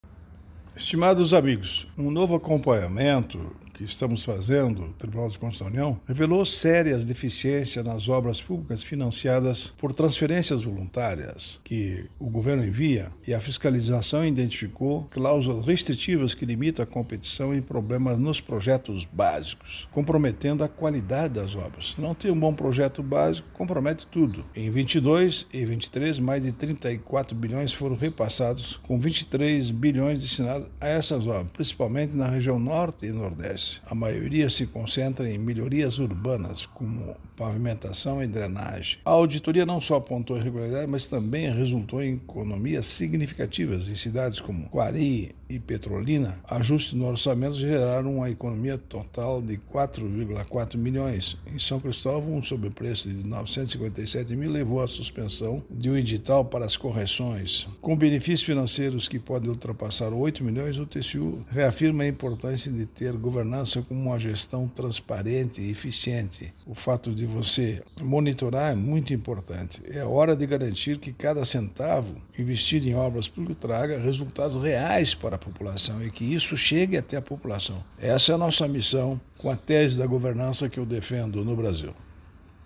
Comentário do ministro do TCU, Augusto Nardes.